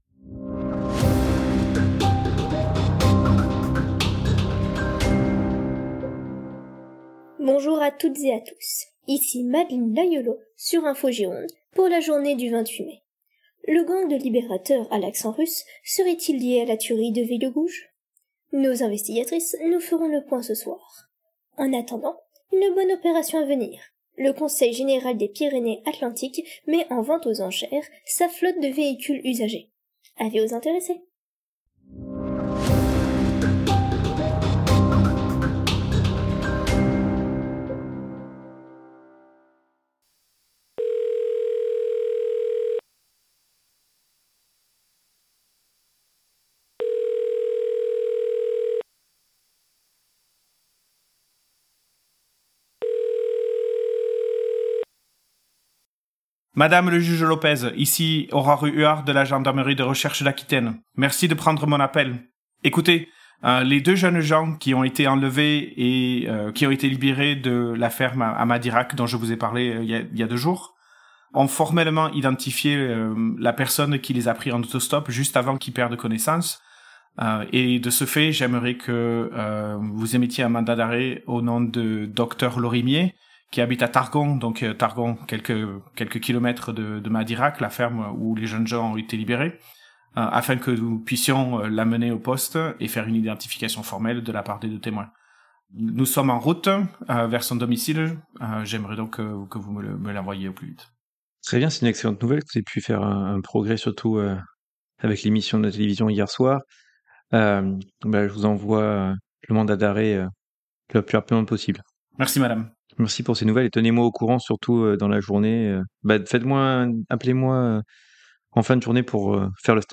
ringing tone, answering, then hanging up
Gendarmerie, sirène extérieure véhicule
Prison cell door open and close